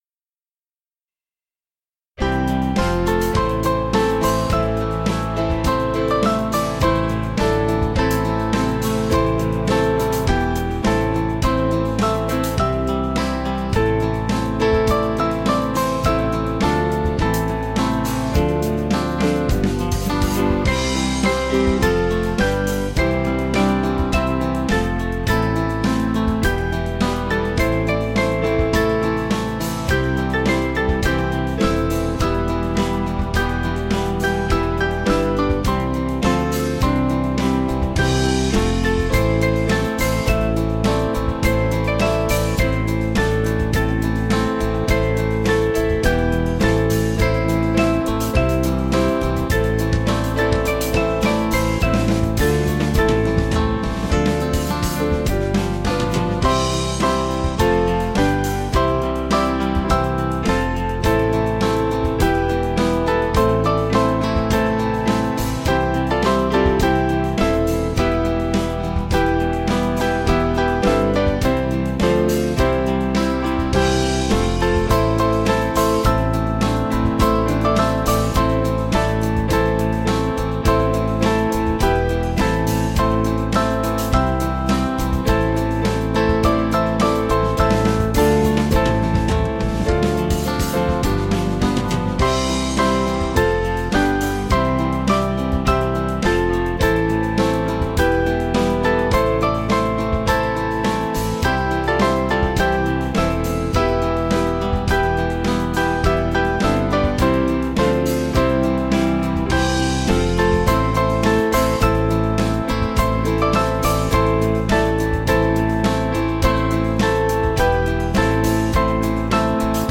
Small Band
(CM)   3/Am 484.8kb